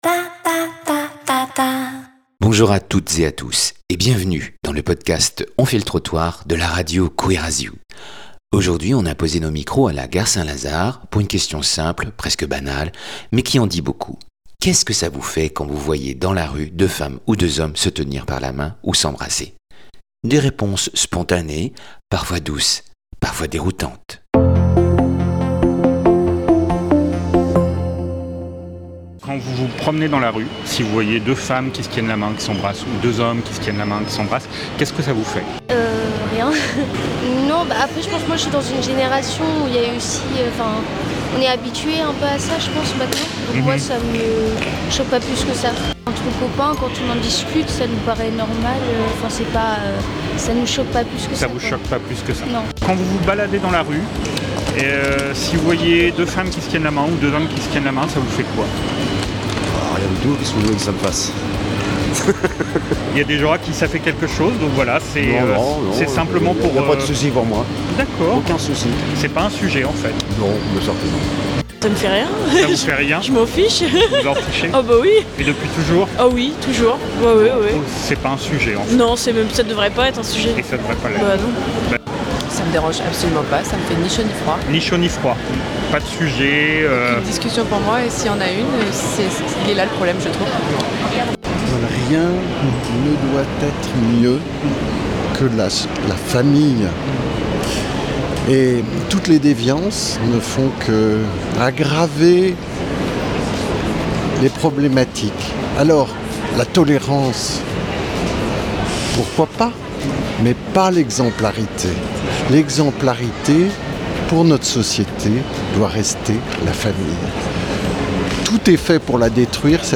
Les passants nous ont livré des réponses spontanées, parfois douces, parfois surprenantes.
💕 Entre réflexions personnelles, anecdotes et petits moments d’émotion, cet épisode est une immersion sonore dans la vie des rues et des regards que nous portons sur l’amour.
Dans ce podcast, c’est nous qui venons vers toi, dans la rue.